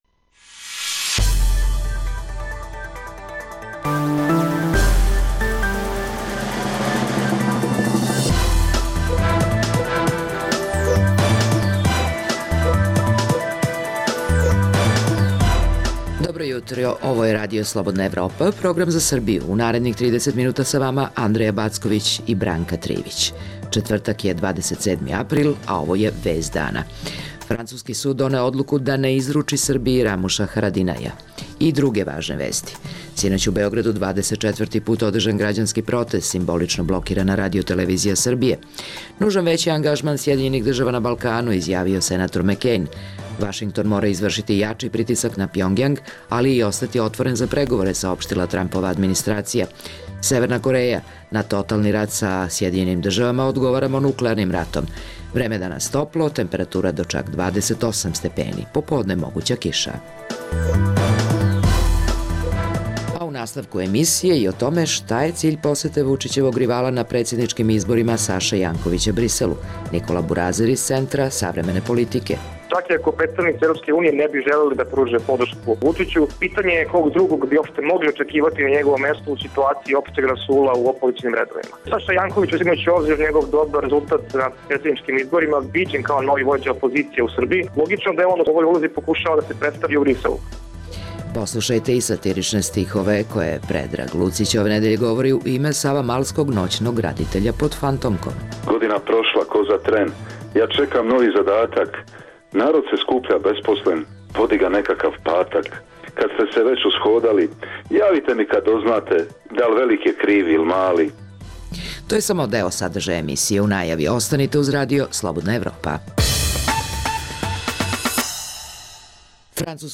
Emisija namenjena slušaocima u Srbiji koja sadrži lokalne, regionalne i vesti iz sveta te tematske priloge o aktuelnim dešavanjima priče iz svakodnevnog života.